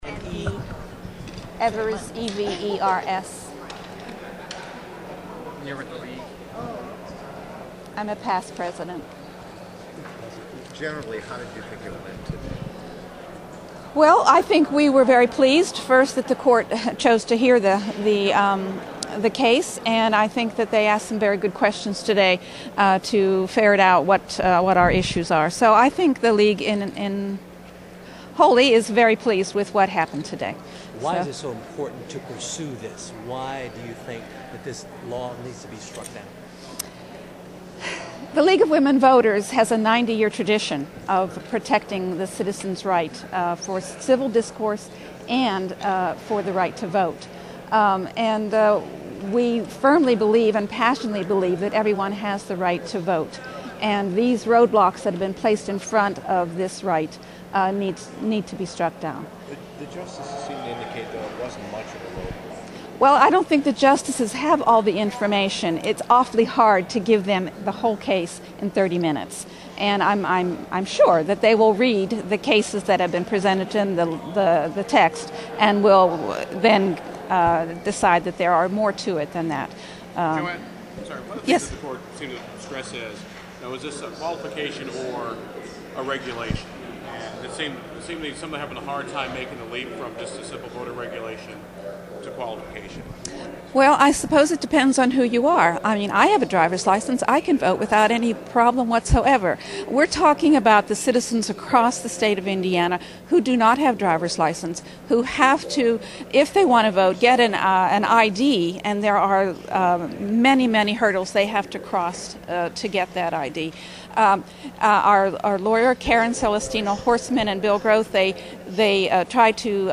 Here are some of the interviews following Supreme Court hearing on Indiana Voter ID.